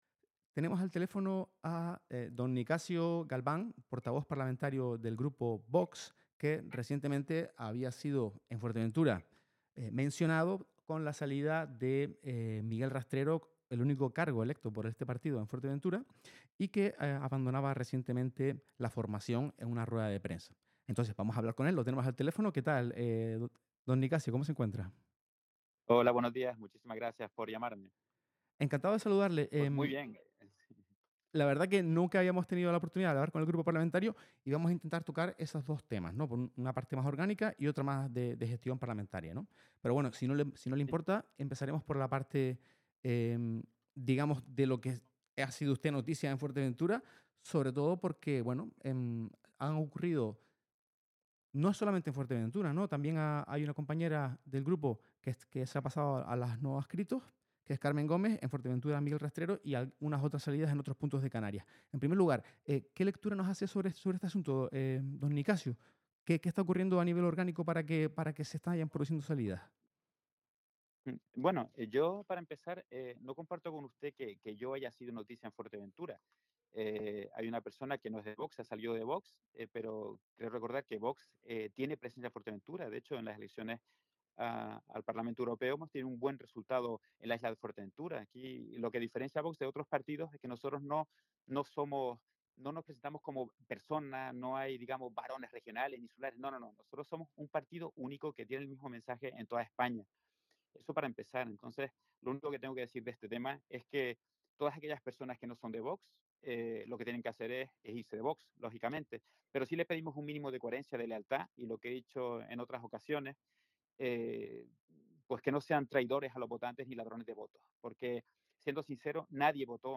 Entrevistas y declaraciones